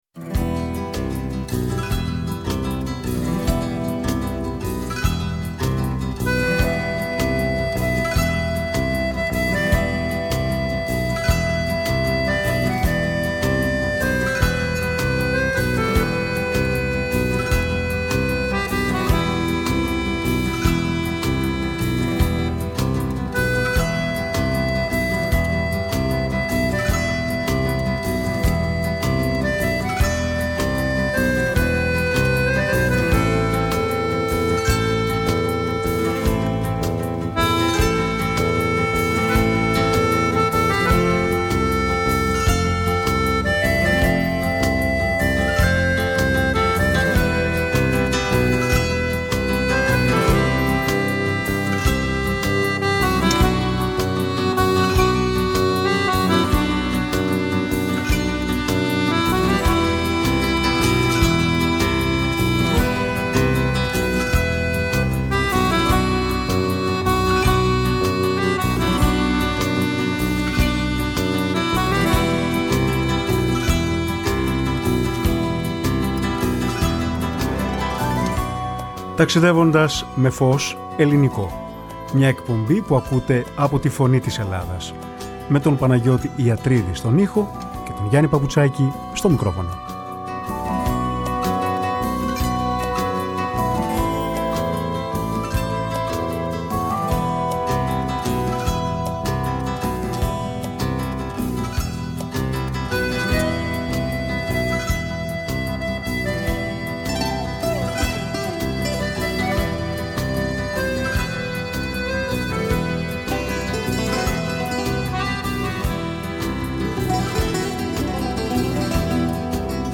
Η ΦΩΝΗ ΤΗΣ ΕΛΛΑΔΑΣ Ταξιδευοντας με Φως Ελληνικο ΜΟΥΣΙΚΗ Μουσική ΣΥΝΕΝΤΕΥΞΕΙΣ Συνεντεύξεις